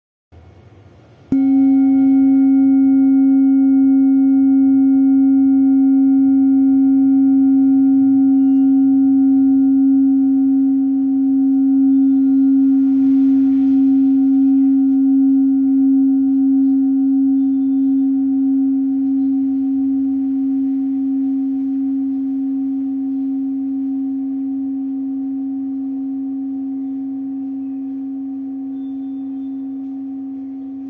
Buddhist Hand Beaten Kopre Singing Bowl, with Antique, Old
Material Bronze
It is accessible both in high tone and low tone .